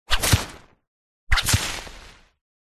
Звуки фэнтези
Лозы листья шипят быстро обвивают удар создан